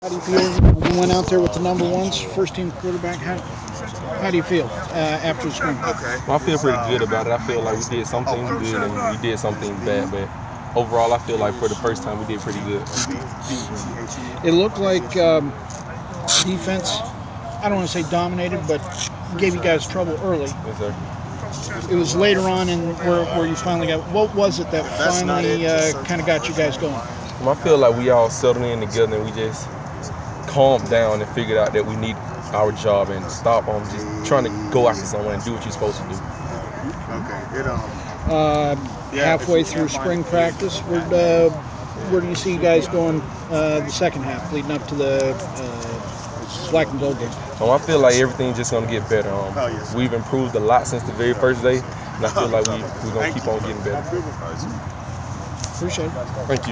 Inside the Inquirer: Post-practice interview